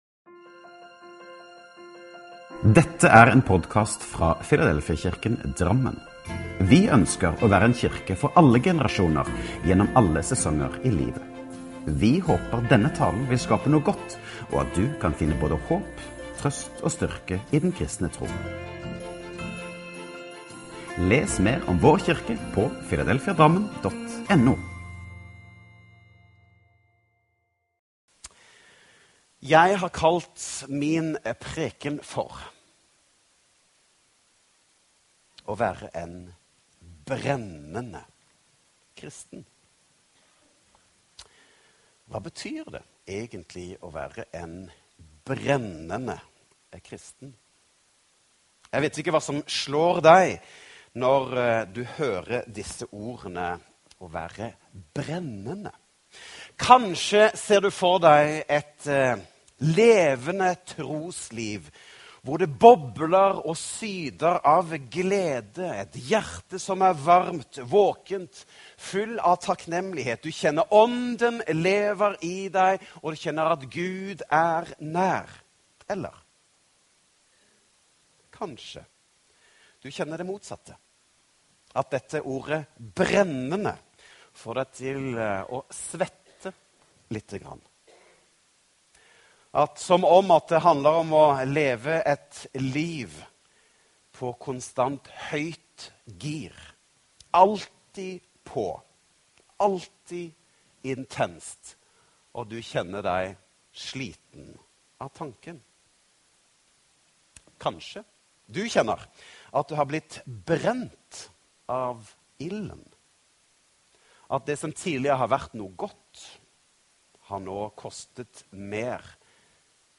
Last ned talen til egen maskin eller spill den av direkte: